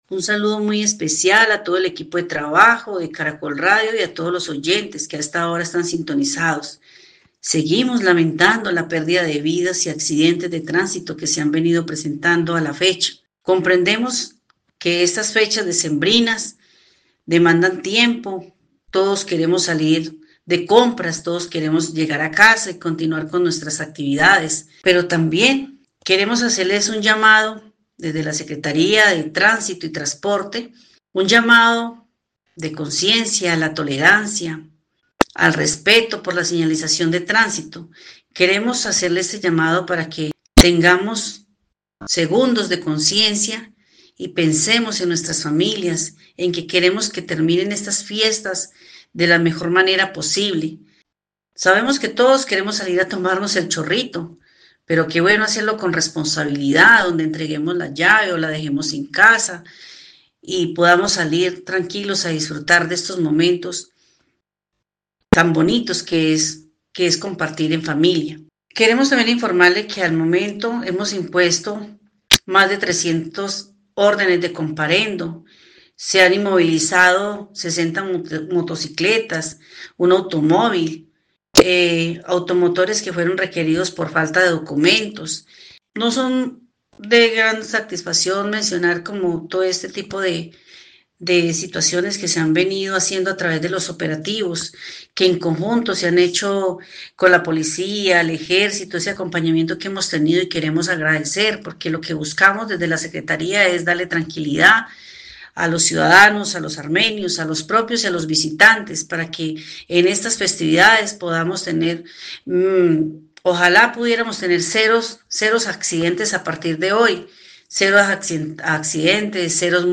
Rosa Buitrago, secretaria (E) de Setta, Armenia